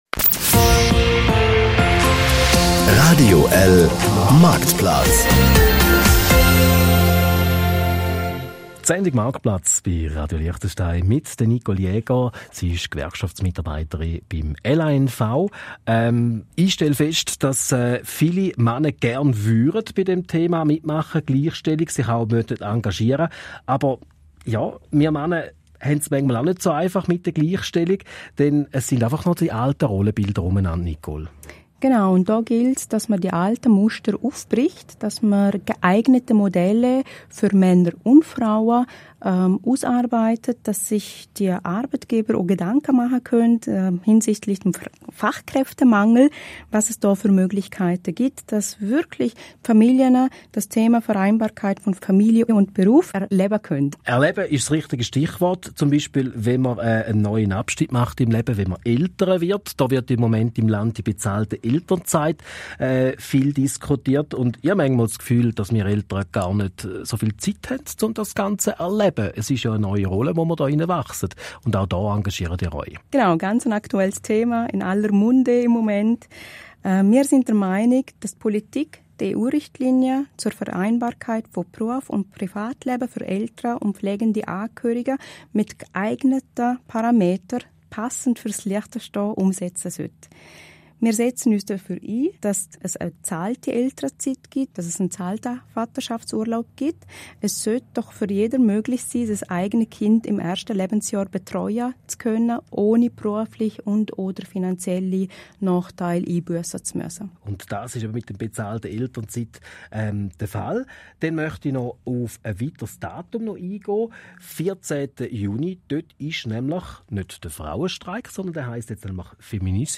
Hier hören Sie den 3. Teil des Radiobeitrages vom 20.02.2023